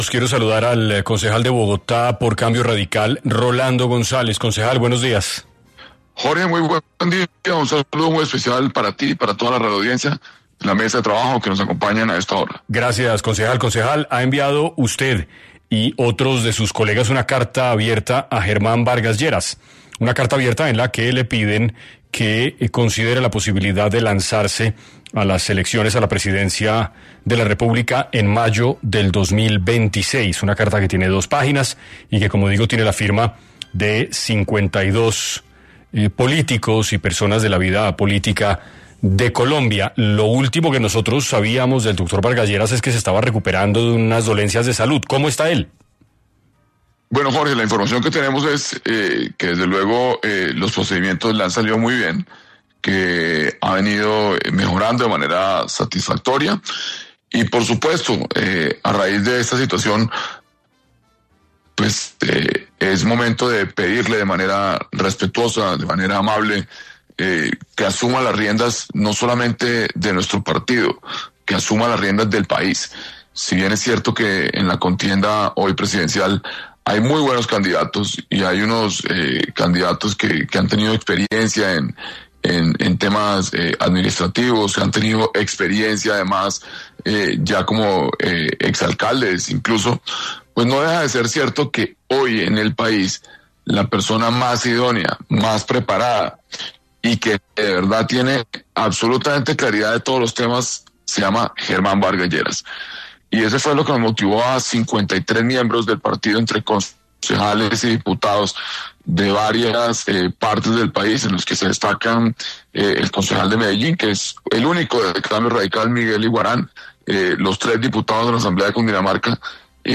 González habló en 6AM sobre la situación actual del país y solicita, junto a otros 53 políticos colombianos, que Germán Vargas Lleras se postule a la Presidencia de la República